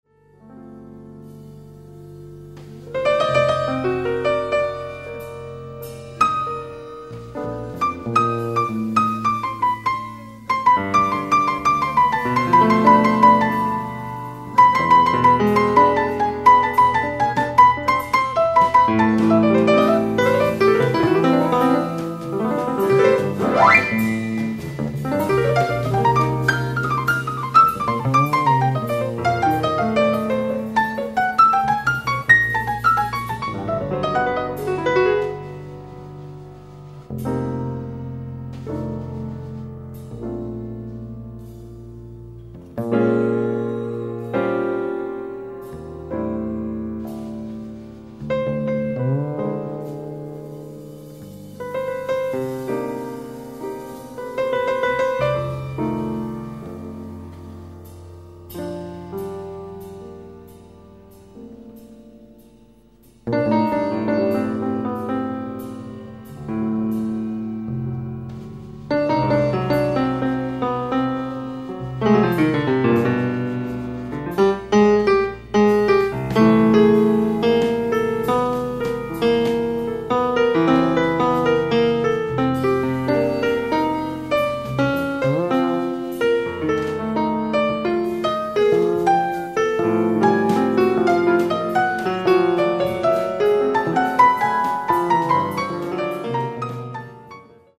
ライブ・アット・ヴィラ・インペリアル、ジェノヴァ、イタリア 07/05/1987
感動を呼ぶ超美音音質！！
※試聴用に実際より音質を落としています。